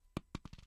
drop_apple.ogg